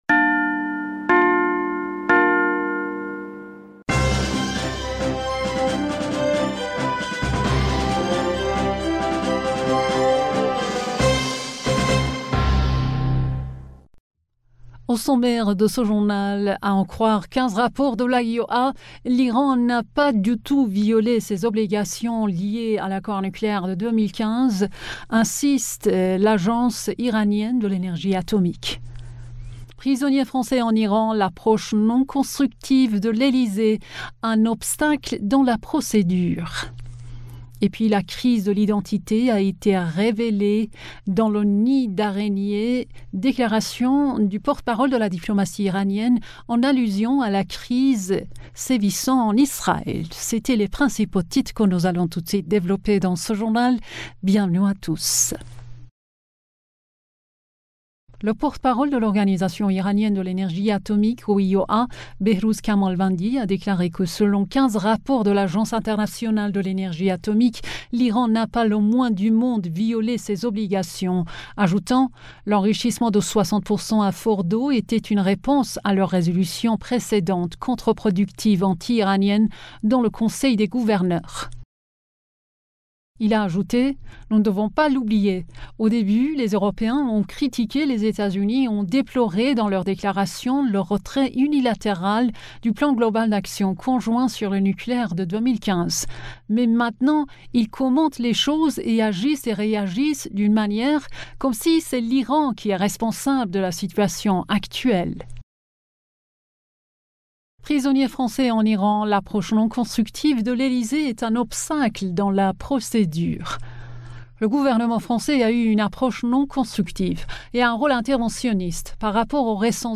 Bulletin d'information du 15 Mars